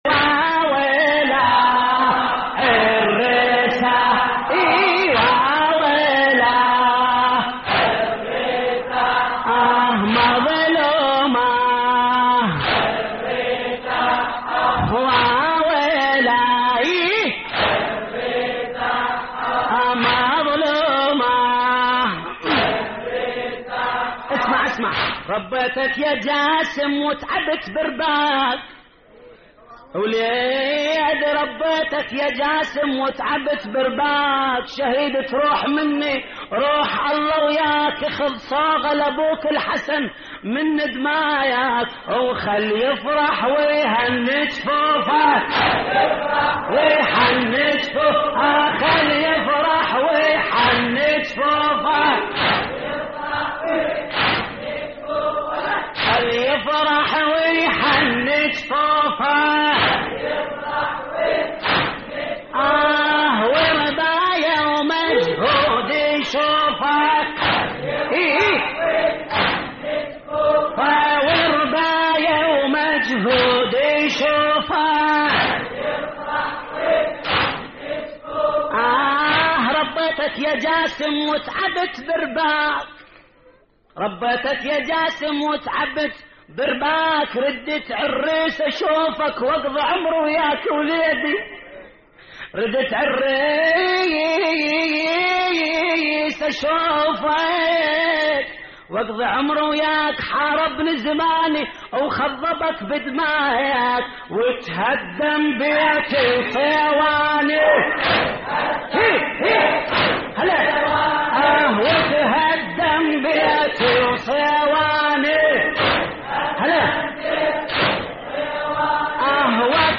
هوسات لحفظ الملف في مجلد خاص اضغط بالزر الأيمن هنا ثم اختر